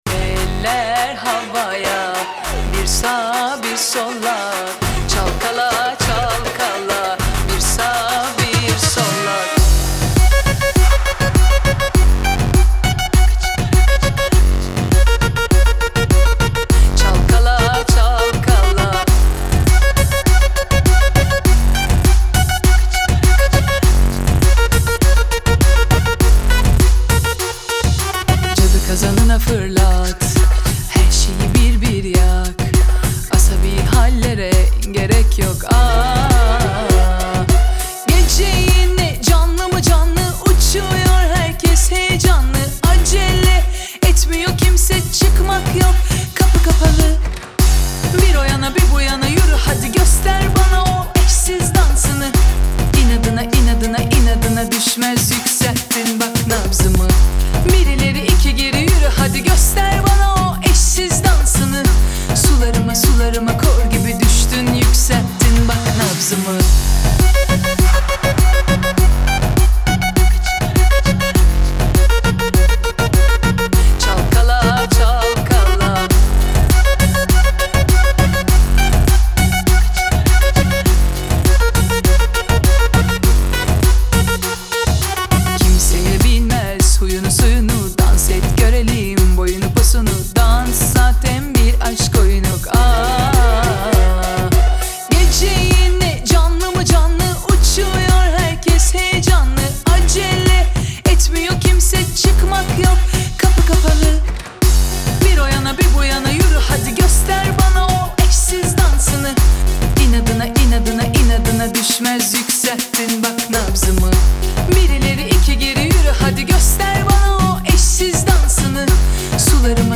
Трек размещён в разделе Турецкая музыка / Поп / 2022.